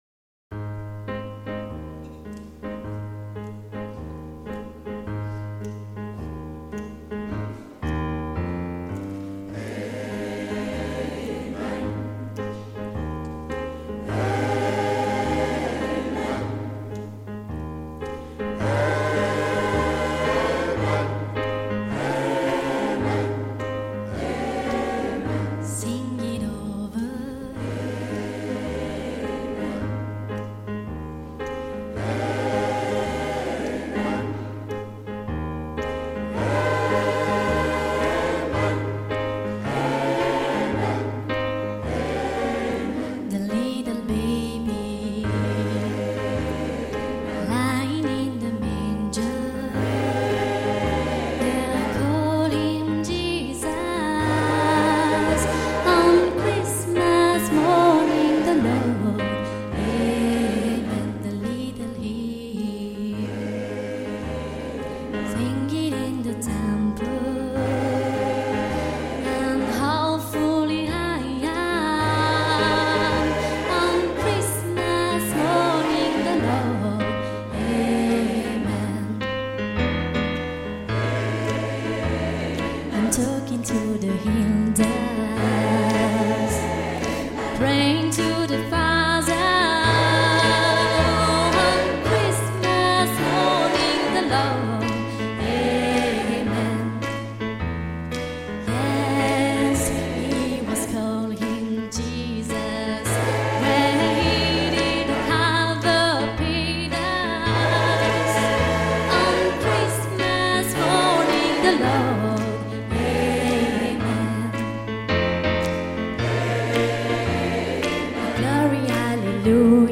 (Gospel)
MP3 / Korg / Guitare / Choeurs / Soliste